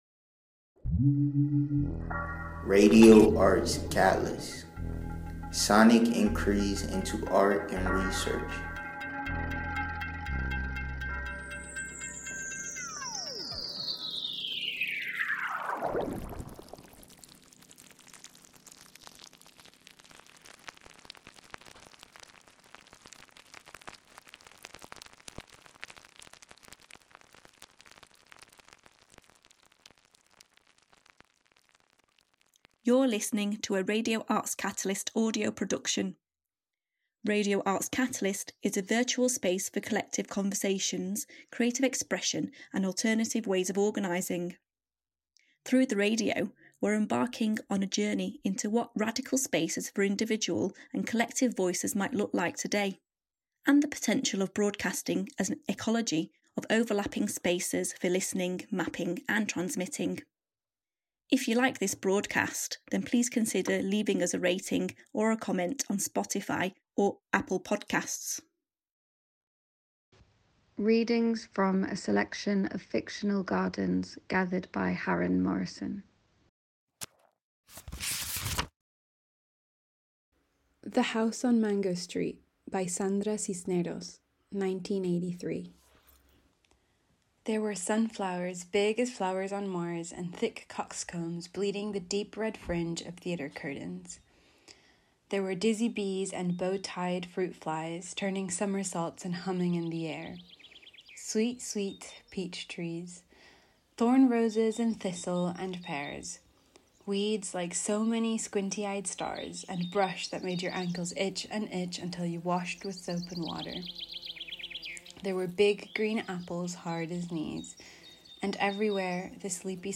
Readings from a selection of Fictional Gardens